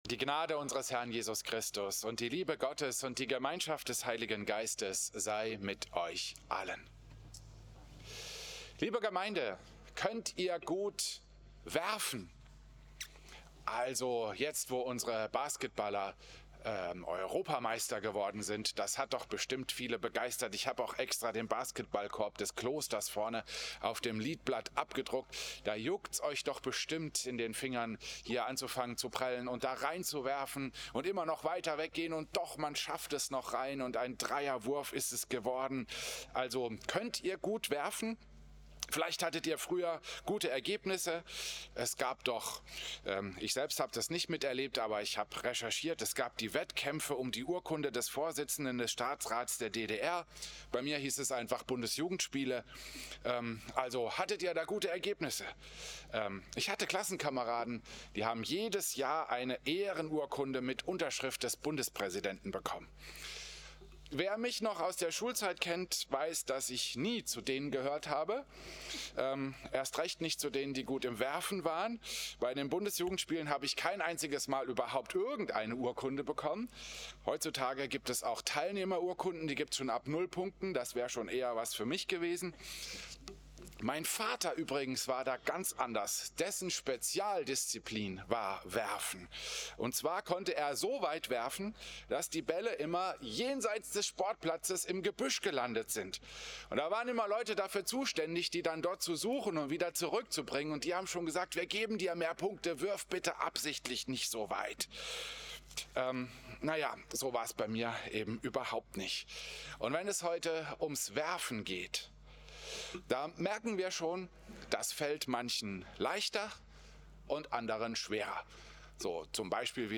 Christus-Pavillon Volkenroda, 28. September 2025
September 2025 | Aktuelles, Predigten Könnt ihr gut werfen?